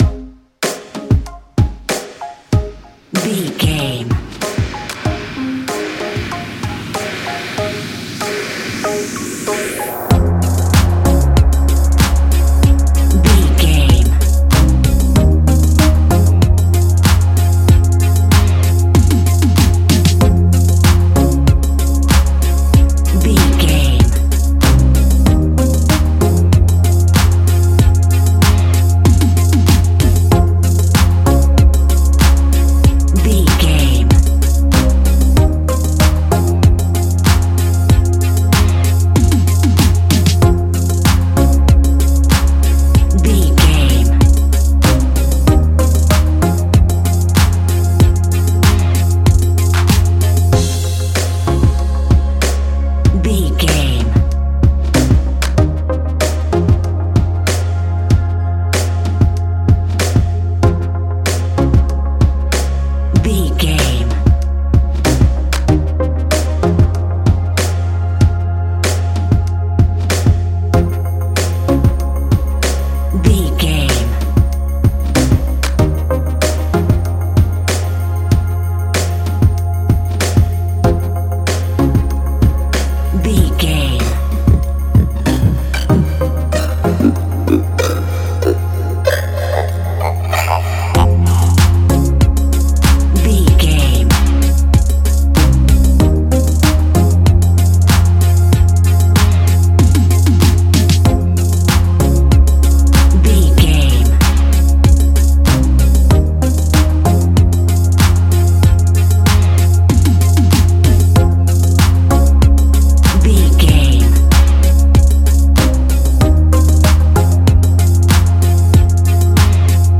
Ionian/Major
C♭
house
electro dance
synths
techno
trance